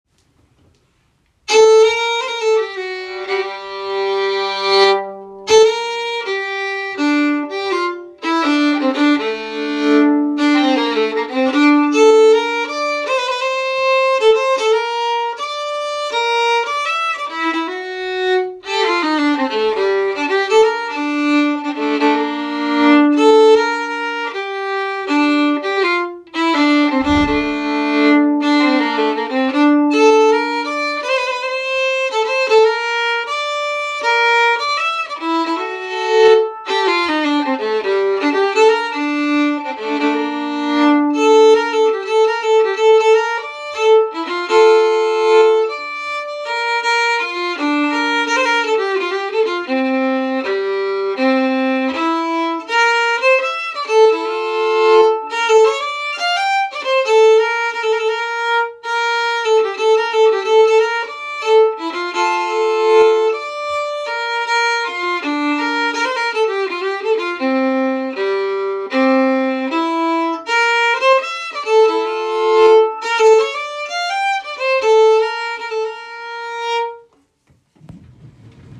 Begrundan är en polska komponerad av Hans Kennemark som del i ett Requiem ”Nu är en dag framliden”.
Stämma: Download